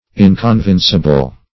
Inconvincible \In`con*vin"ci*ble\, a. [L. inconvincibilis.